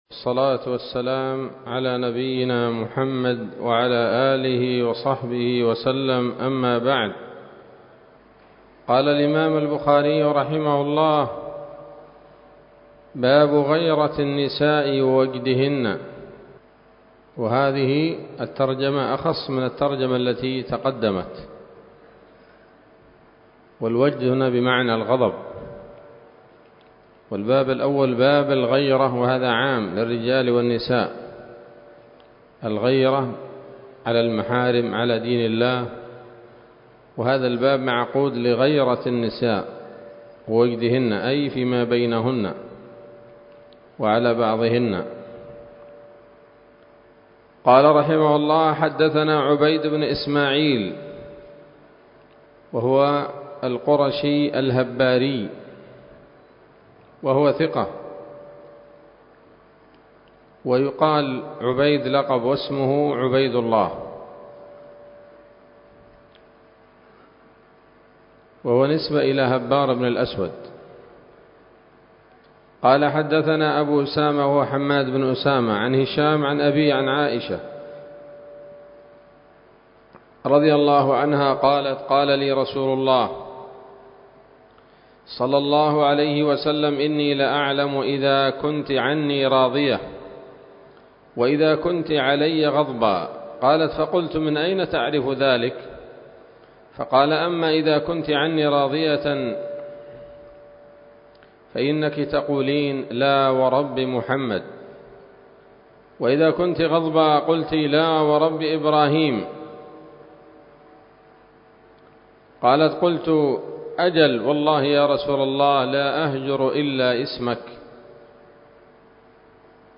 الدرس الخامس والثمانون من كتاب النكاح من صحيح الإمام البخاري